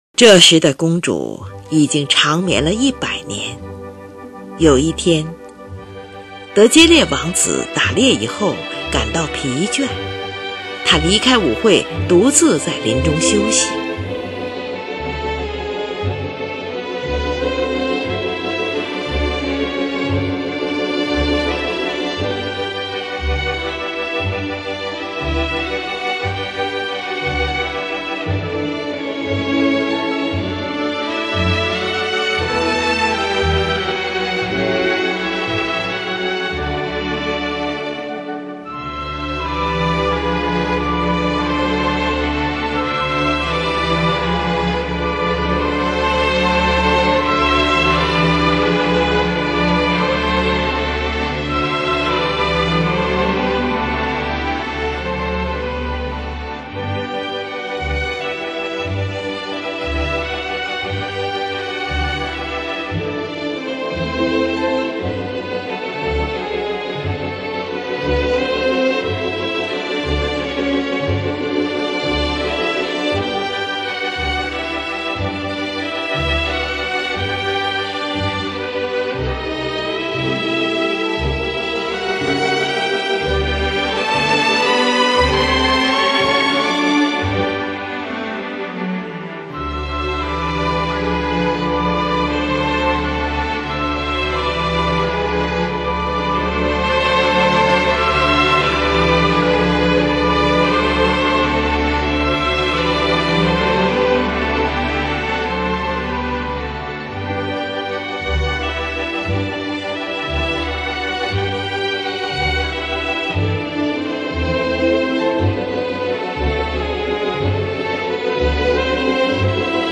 这出舞剧以它那充满节日的欢乐，明朗的、亲切抒情的音乐，揭示出人的内心世界的美。